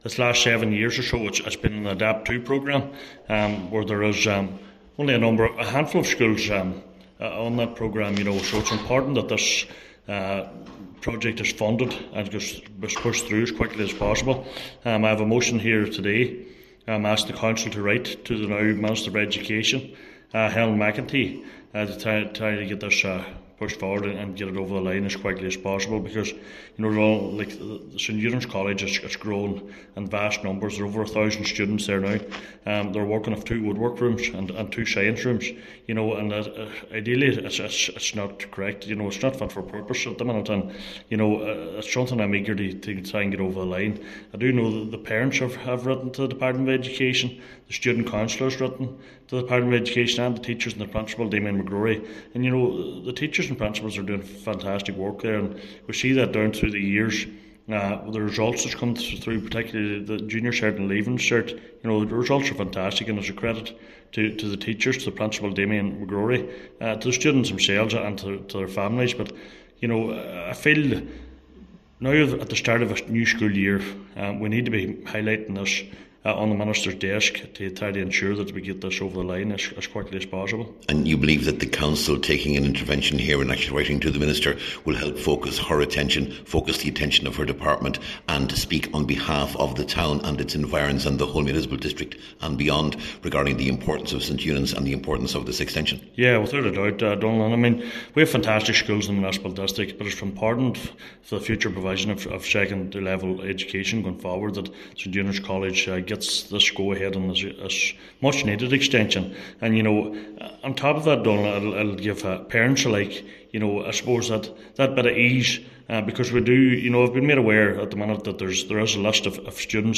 Cllr Kelly says with the school’s inclusion on the ‘Adapt 2’ programme for the past number of years, now is the time for this to progress……..